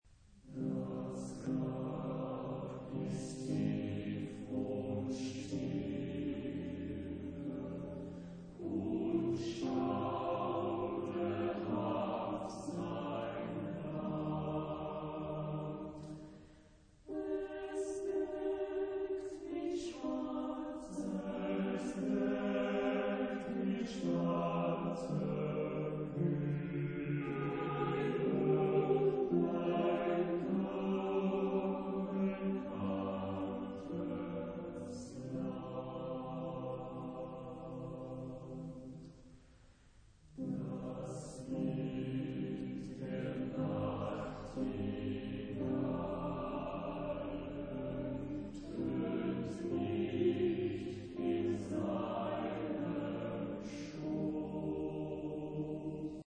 Genre-Stil-Form: weltlich ; Chor ; romantisch
Charakter des Stückes: andächtig
Chorgattung: SATB  (4 gemischter Chor Stimmen )
Tonart(en): c-moll